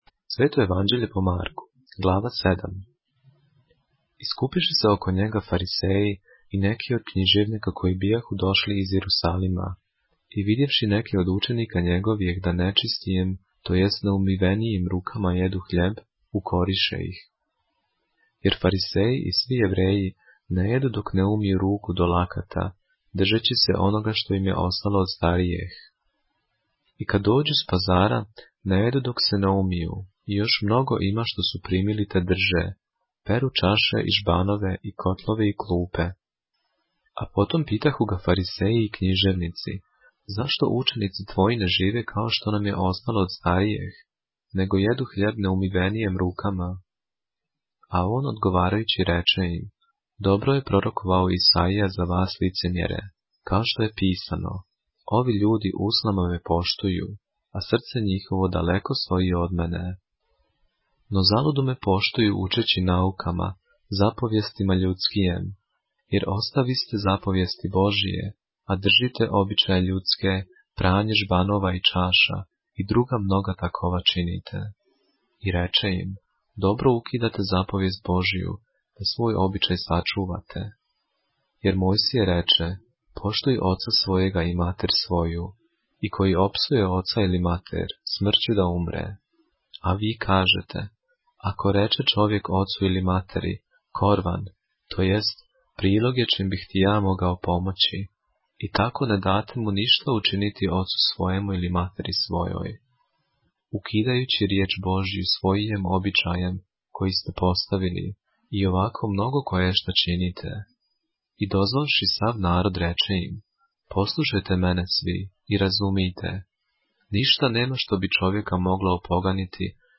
поглавље српске Библије - са аудио нарације - Mark, chapter 7 of the Holy Bible in the Serbian language